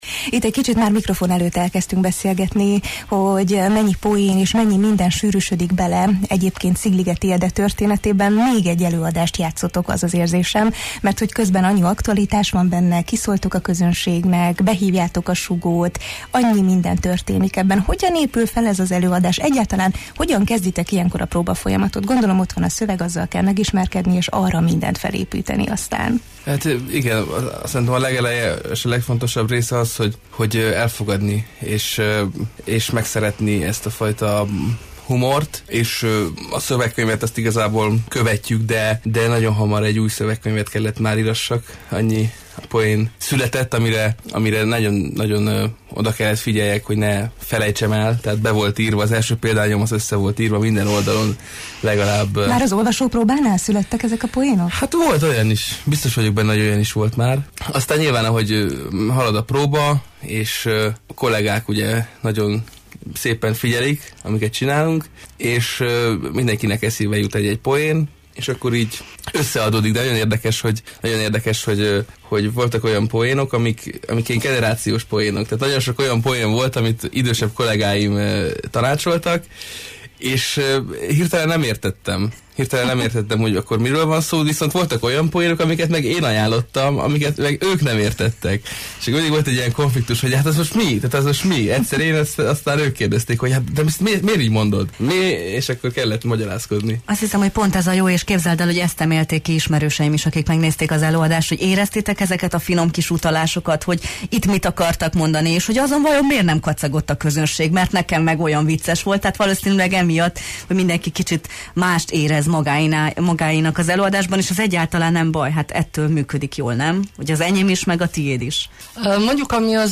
színművészeket kérdeztük az előadás kapcsán a Jó reggelt, Erdély!-ben: